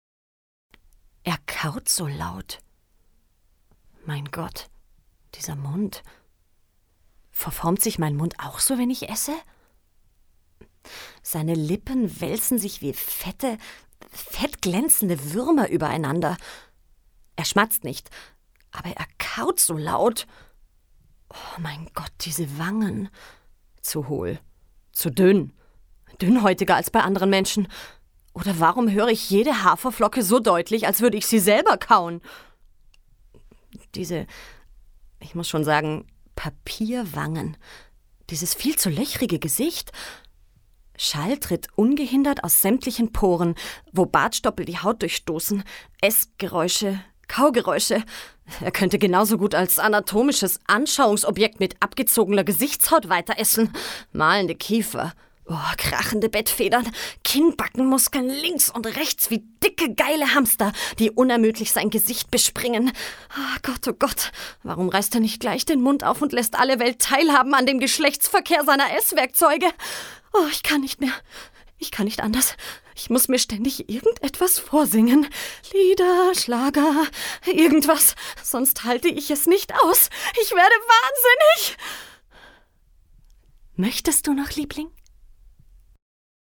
Ehe-Szene